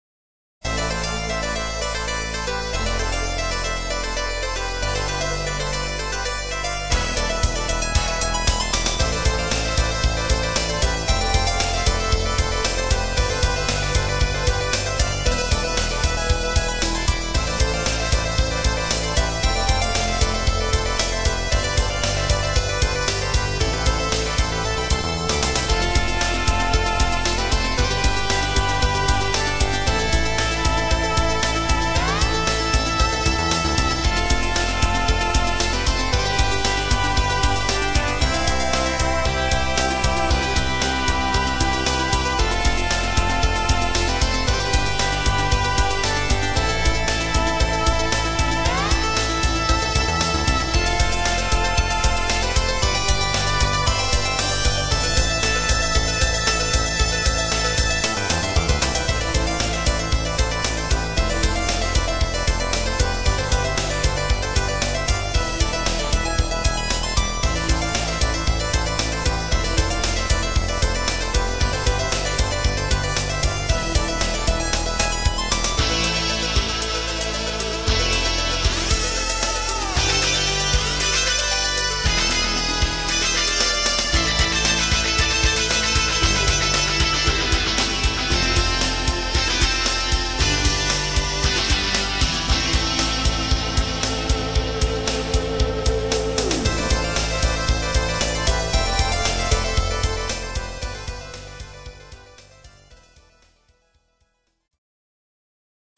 同じくFM音源とMIDIの同期を取るテスト用に作った曲です。
※音源はＳＣ８８Ｐｒｏ＋ＦＭ音源